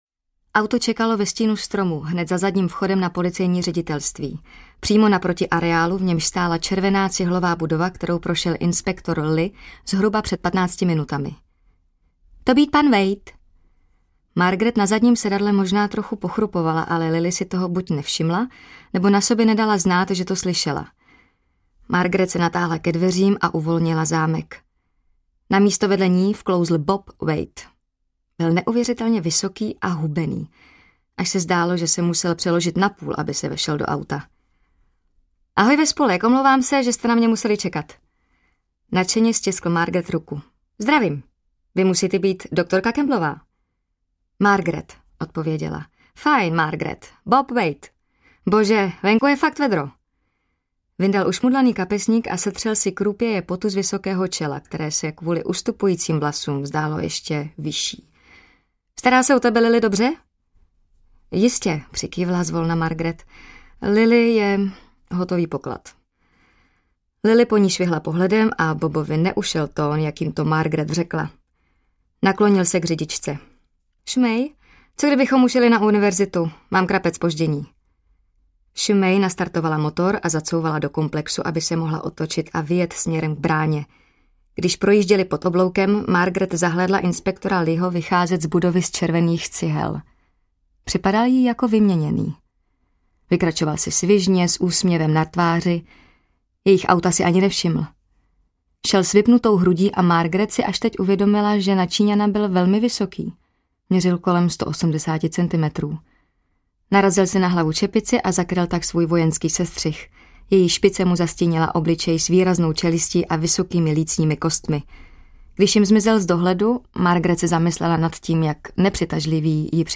Audiobook
Read: Martin Myšička